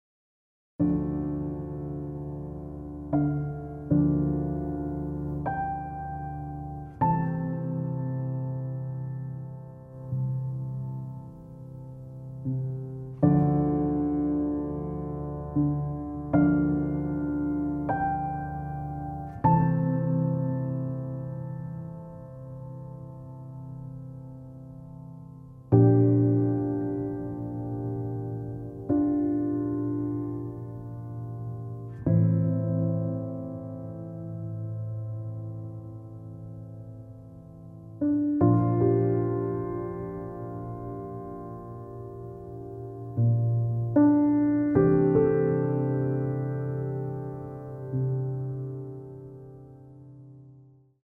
Celtic-flavoured orchestral score